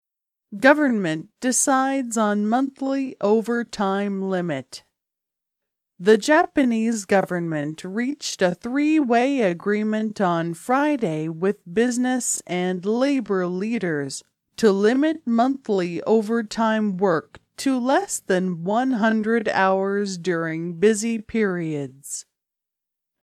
「ややスロー音声」を再生して改行の区切りごとに一時停止し、次の点に注意しながらゆっくりリピートしてください。